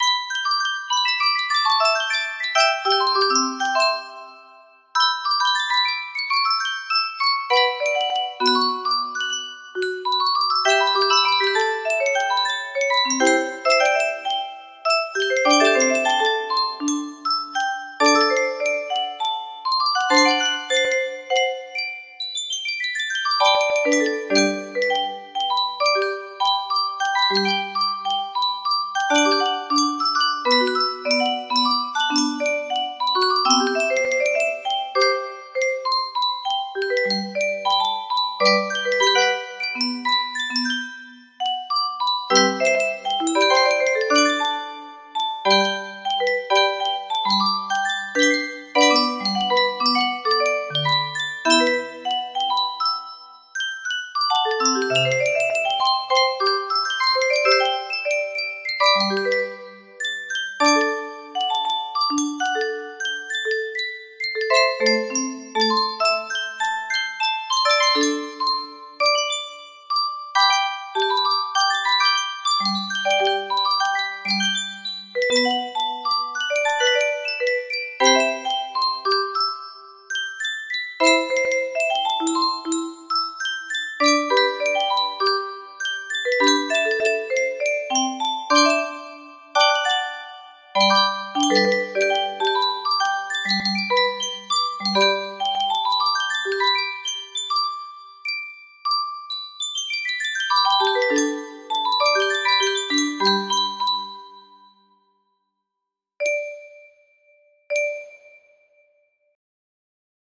Threads Of Gold 2 music box melody
Full range 60